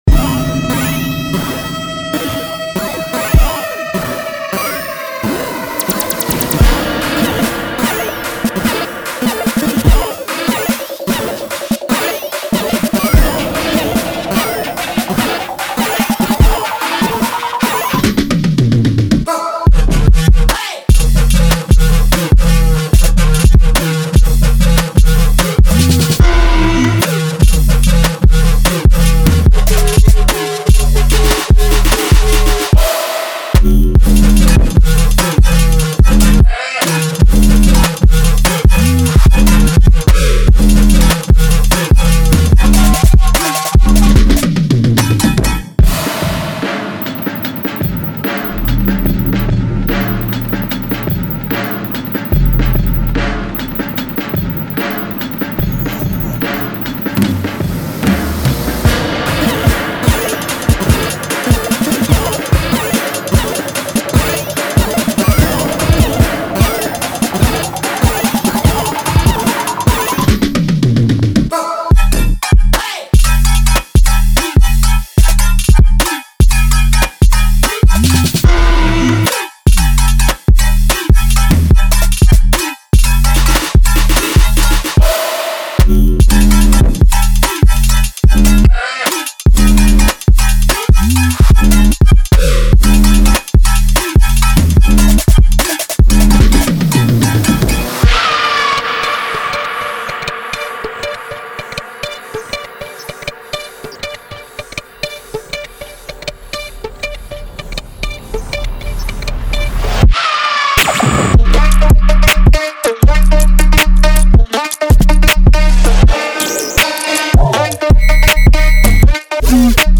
• 120 Snappy drum hits
• 20 Twisted drum loops (with stem bounce)
• 12 Powerful bass loops (+MIDI)
• 8 Evil chord loops (+MIDI)
• 36 SFX
• Tempos – Ranging from 125-160 BPM